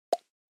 aimlab击中音效2.mp3